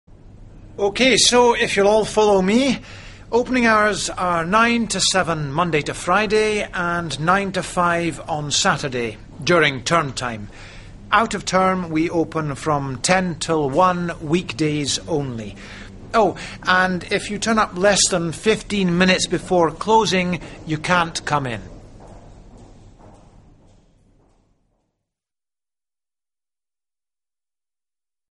Listen as he shows a group of new students around the library. Find three mistakes in the information below.